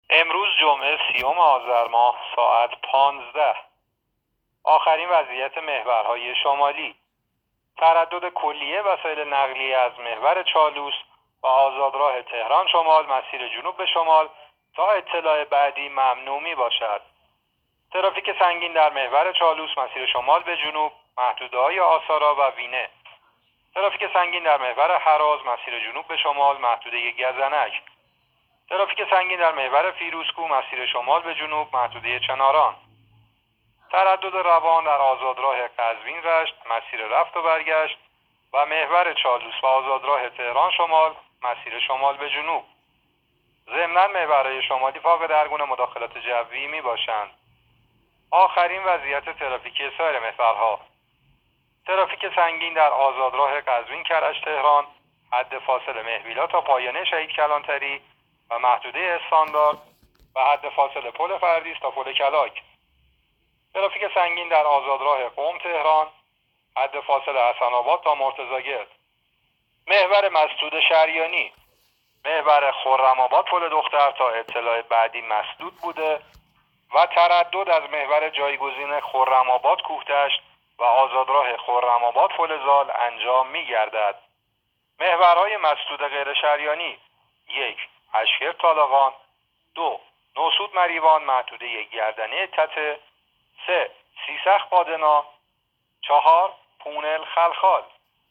گزارش رادیو اینترنتی از آخرین وضعیت ترافیکی جاده‌ها تا ساعت ۱۵ سی‌ام آذر؛